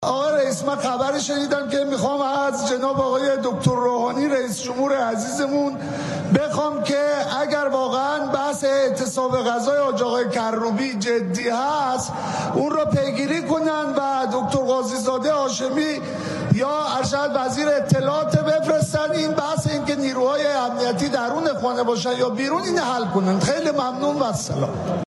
سخنان کواکبیان در مورد اعتصاب غذای کروبی در مجلس